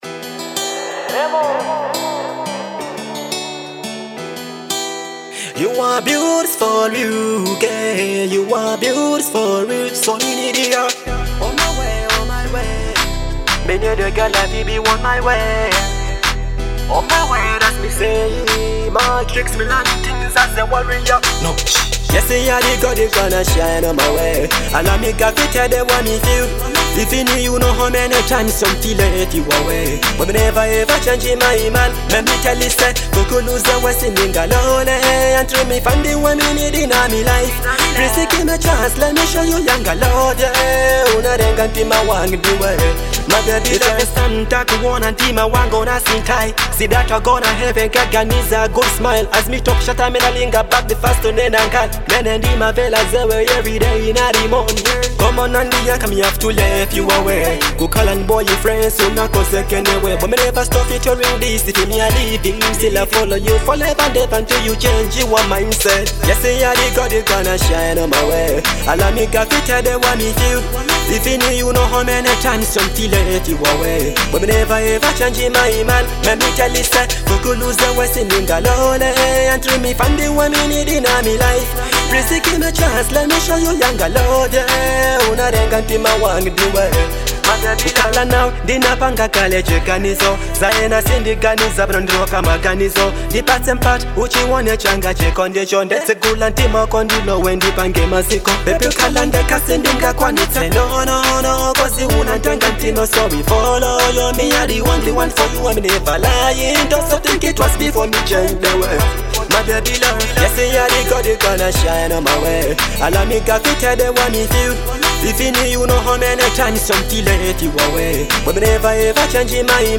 Genre : Dancehall